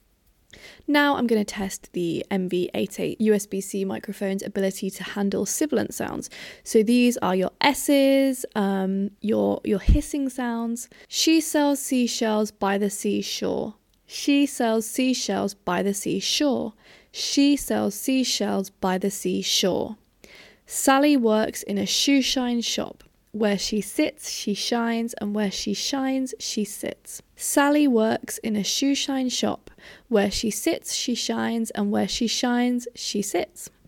Fricatives/sibilance
As you can hear, there is one moment where my voice almost crashes against the microphone. However, the mic handled the rest of the sibilant sounds really well.